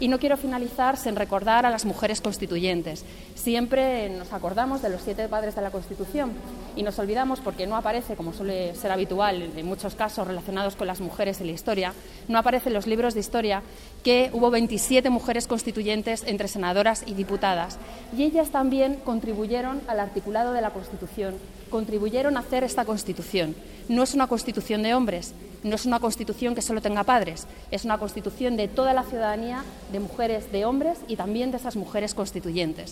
La directora del Instituto de la Mujer, Araceli Martínez, habla de la importancia de las mujeres en la redacción de la Constitución española.